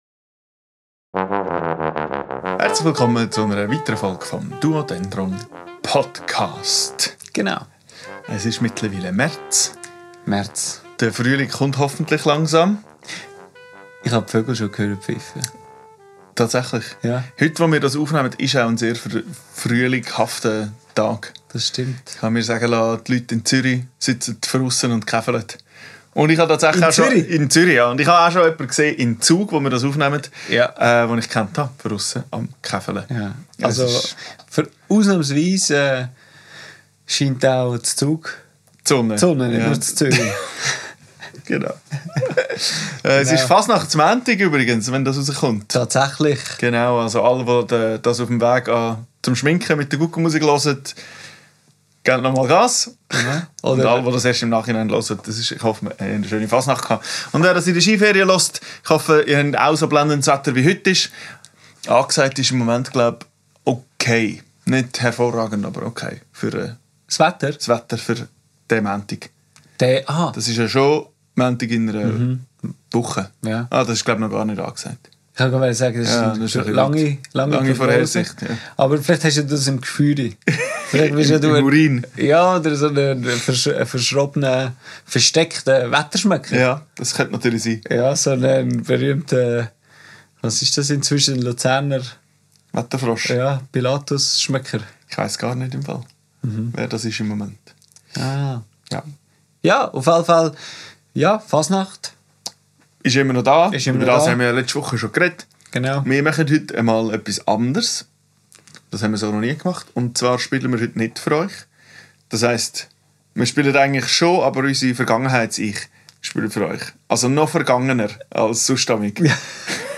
In dieser Folge hören wir uns unsere eigene Musik an. Genauer, Aufnahmen von Improvisationen aus unserer Session auf der Klewenalp. Natürlich gibt es dann jeweils einen Kommentar dazu.